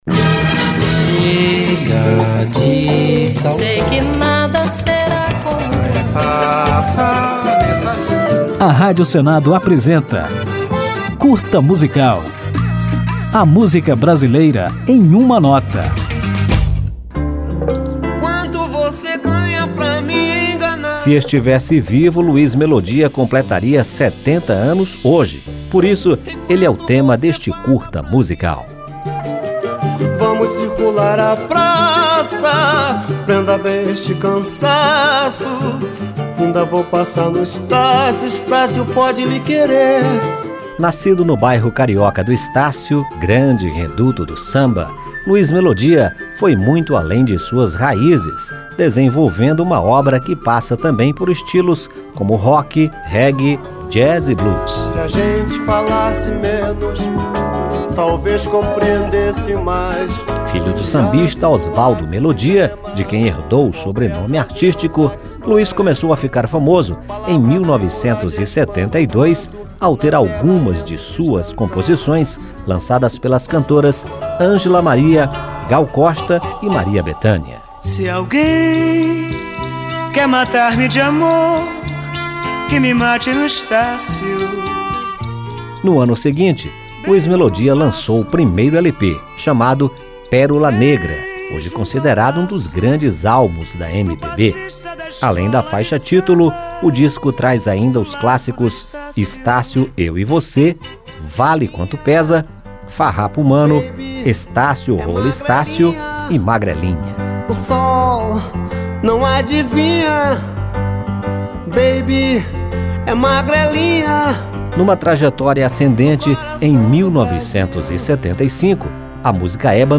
Conjunto de pílulas radiofônicas sobre a MPB, nas quais o ouvinte pode conferir fatos, curiosidades, informações históricas e ainda ouvir uma música ao final de cada edição.